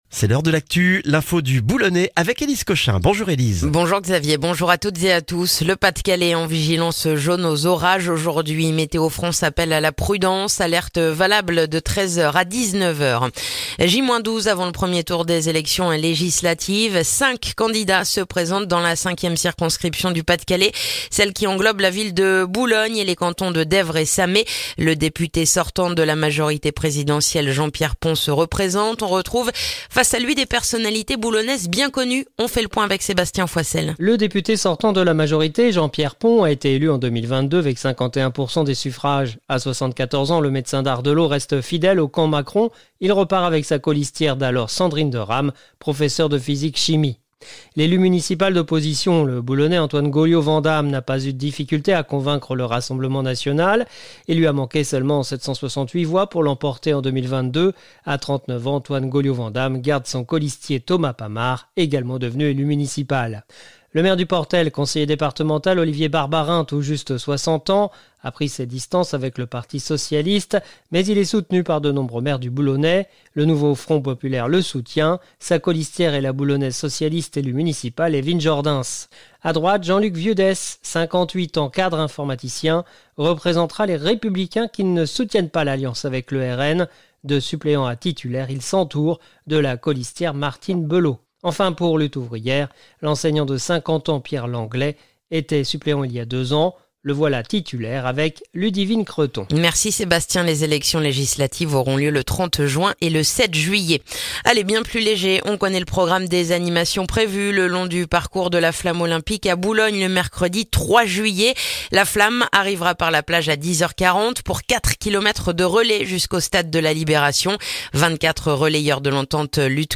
Le journal du mardi 18 juin dans le boulonnais